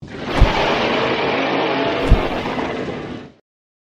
Gryphon Roar and Stomp
Gryphon Roar and Stomp is a free sfx sound effect available for download in MP3 format.
yt_U-r1eYjhVZw_gryphon_roar_and_stomp.mp3